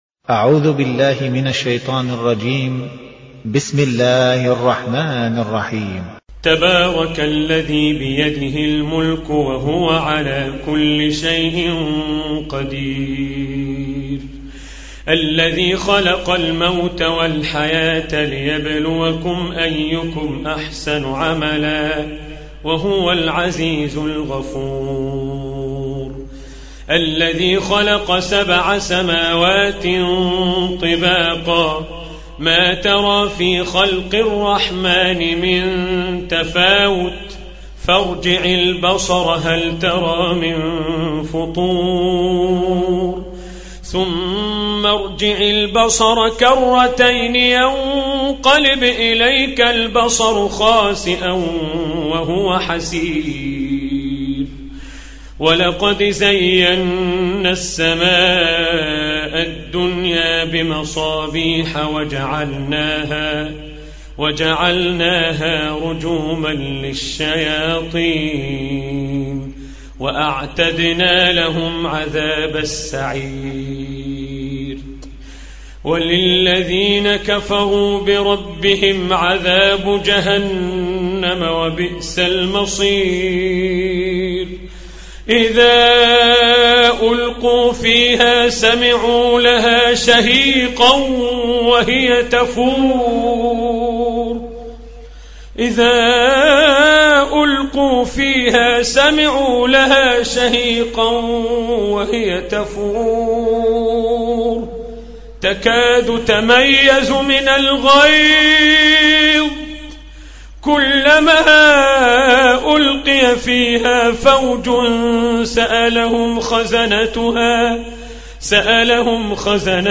Чтение Корана